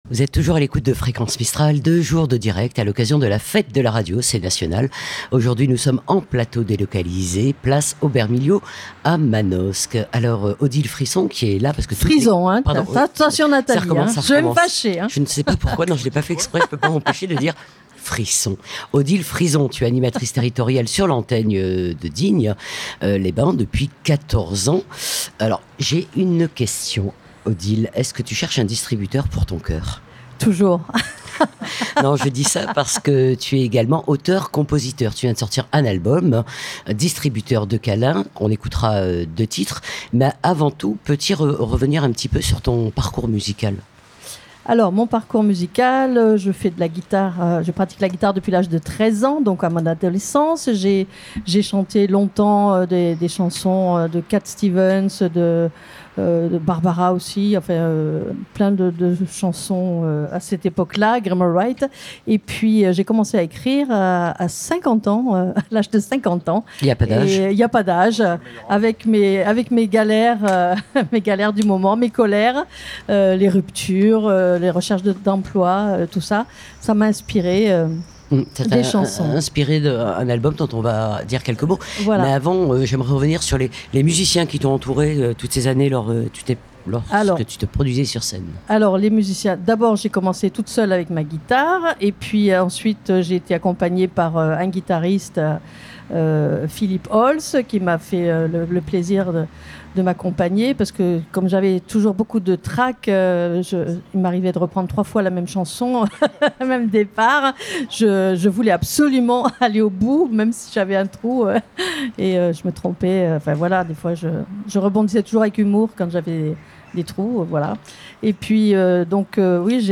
Le 5 et 6 juin 2025 c'était la fête de la Radio dans toute la France. A cette occasion, toute l'équipe de Fréquence Mistral s'est retrouvée afin de vous proposer un plateau délocalisé en direct sur toute la journée sur Manosque.